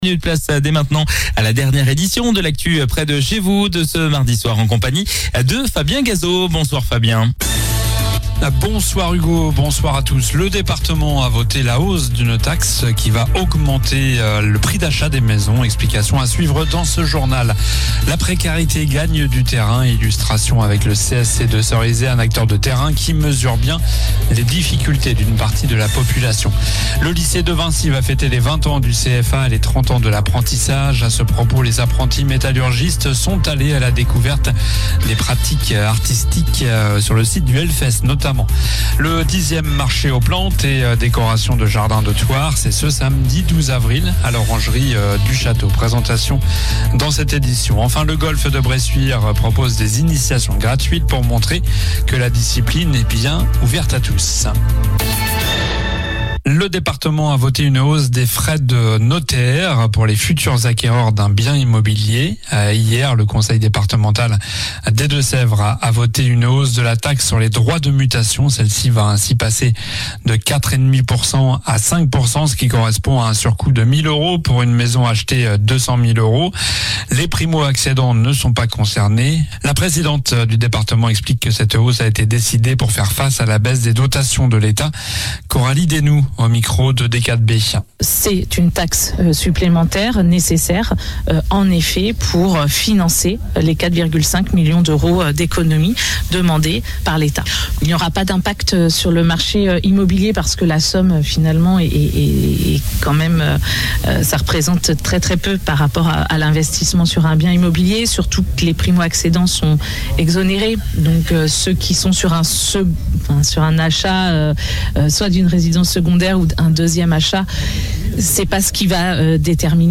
Journal du mardi 08 avril (soir)